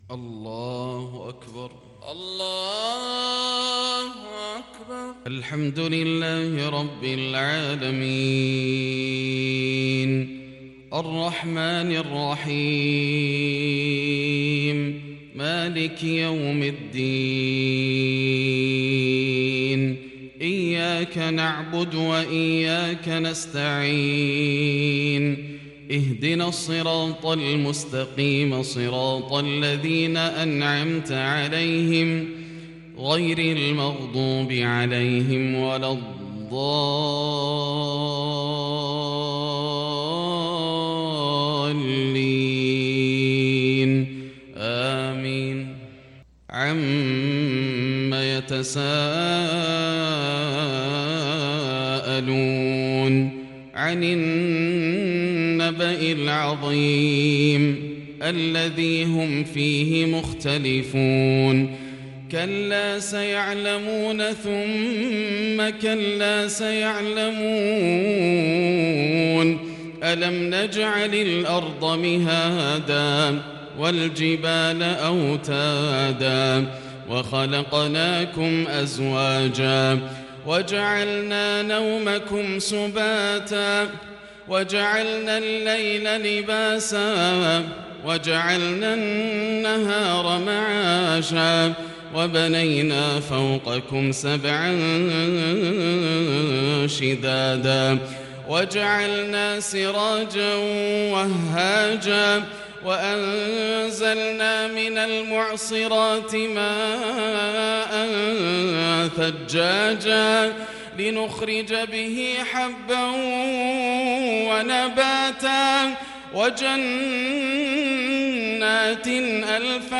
صلاة الفجر للشيخ ياسر الدوسري 24 صفر 1442 هـ
تِلَاوَات الْحَرَمَيْن .